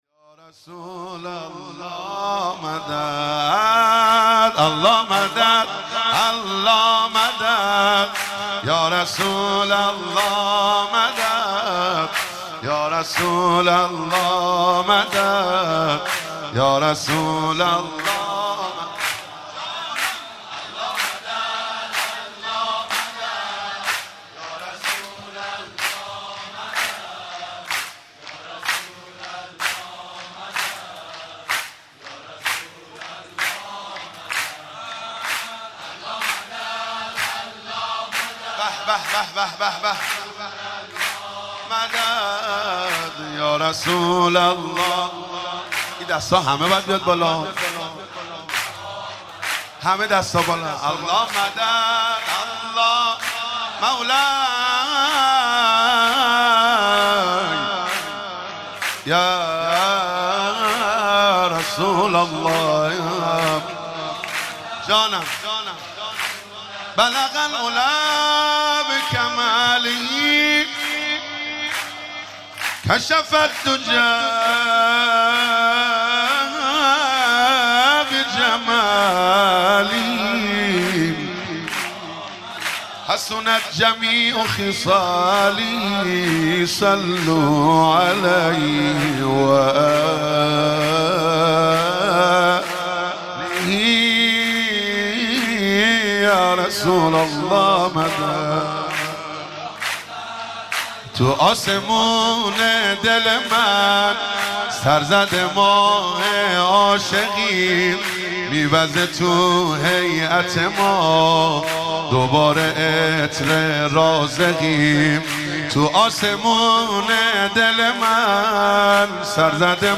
دانلود مولودی های عید مبعث